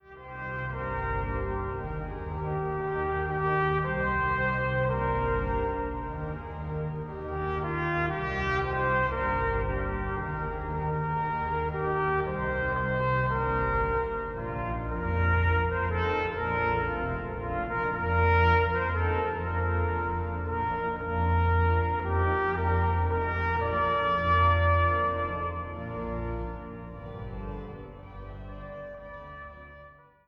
Trompete